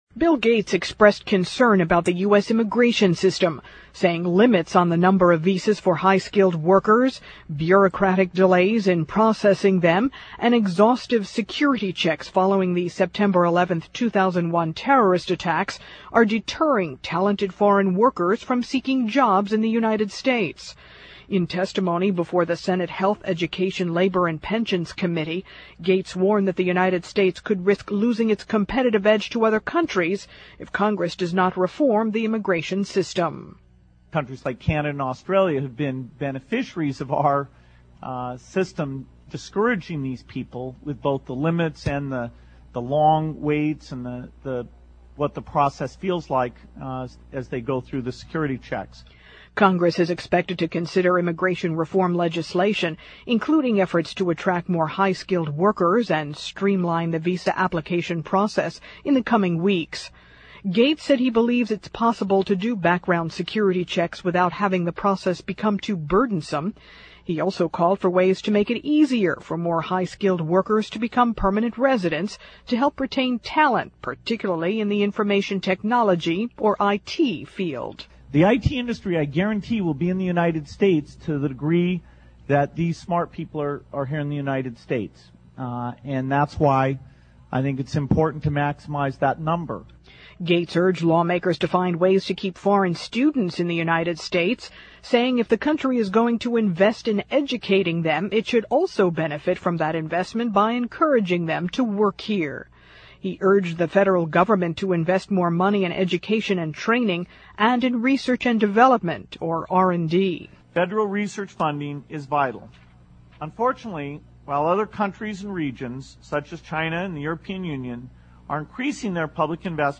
2007年VOA标准英语-Microsoft's Gates Urges US to Seek More High-Sk 听力文件下载—在线英语听力室